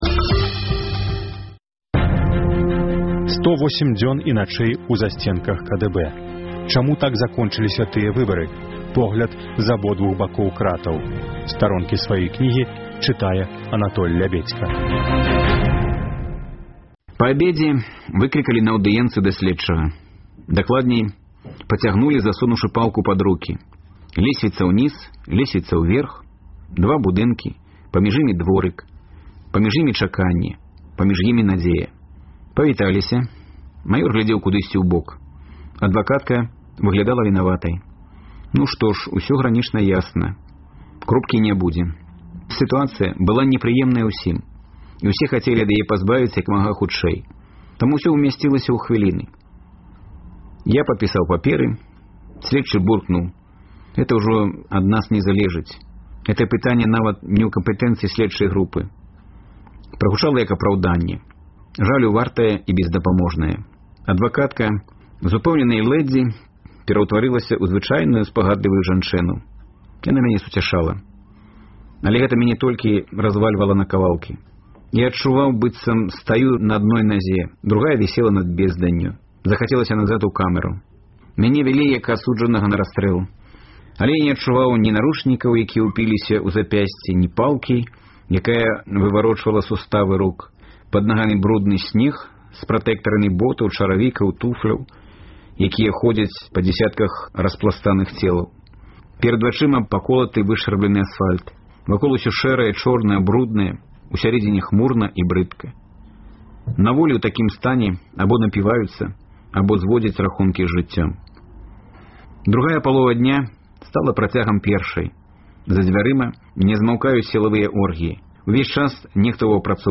На хвалях Радыё Свабода гучаць разьдзелы кнігі Анатоля Лябедзькі «108 дзён і начэй у засьценках КДБ» у аўтарскім чытаньні.